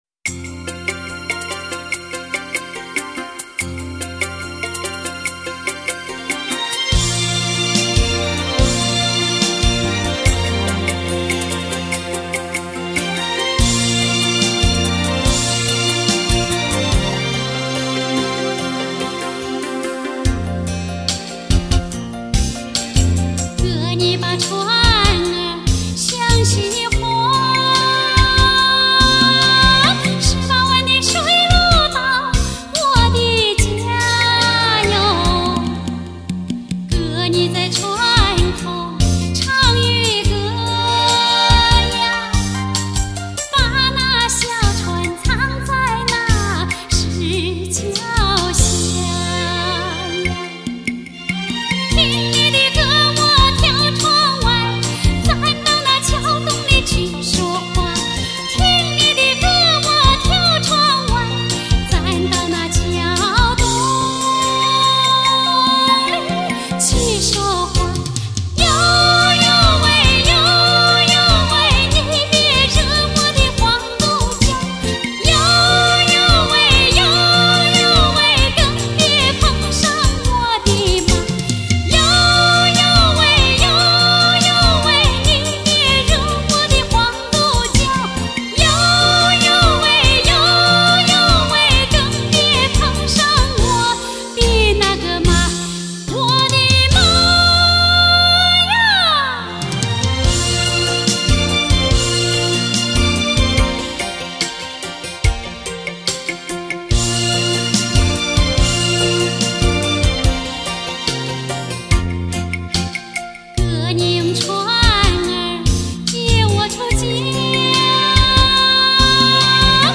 中国当今最负盛名的女高音歌唱家，被誉为中国民歌皇后，她是东方美的化身，她的歌是艺术王冠上的明珠。